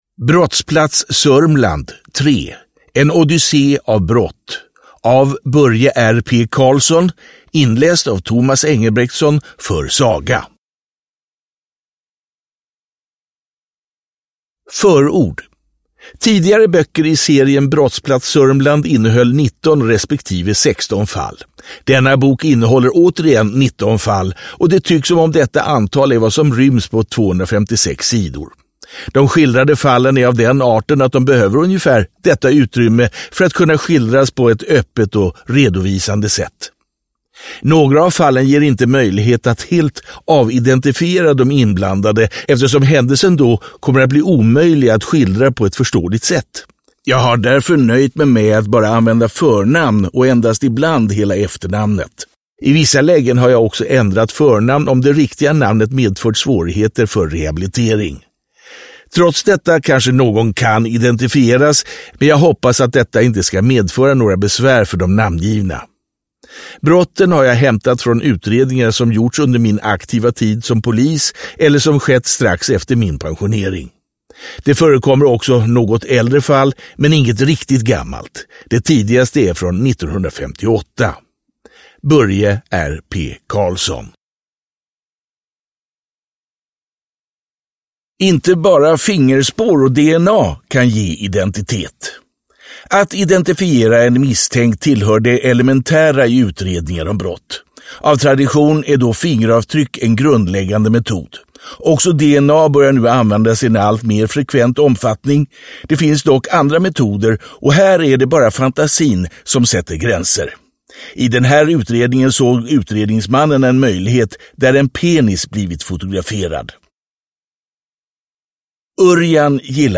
Brottsplats Sörmland. 3, En odyssé av brott – Ljudbok – Laddas ner